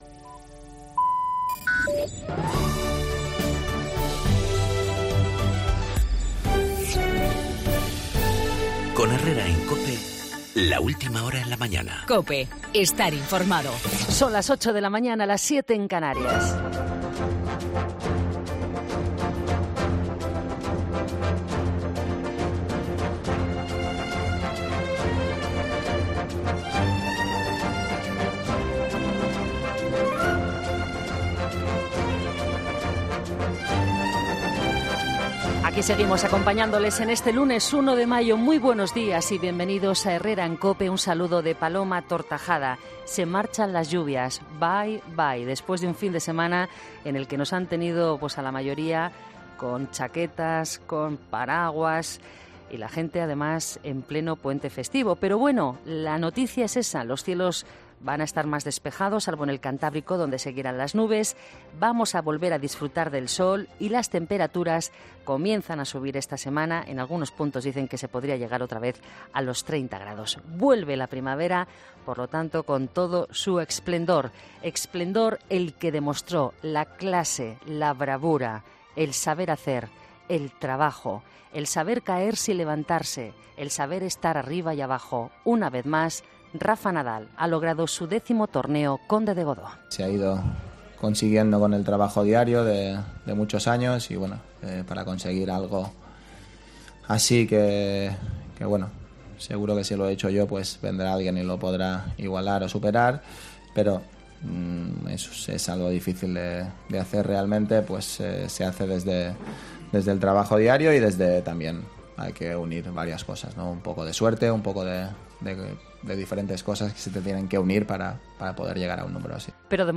El deporte con Juanma Castaño.
Tiempo de análisis con Gloria Lomana, Ignacio Camacho e Inocencio Arias. Entrevista con Unai Sordo, Secretario General de CCOO en el País Vasco.